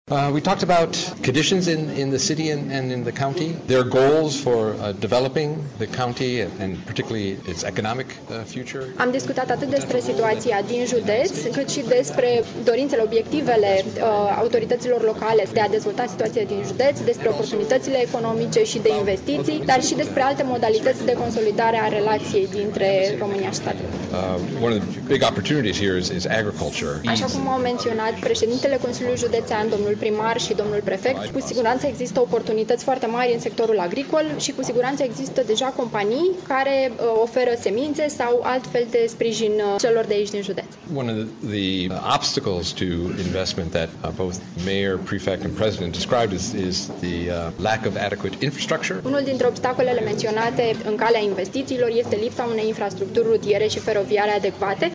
Hans Klemm: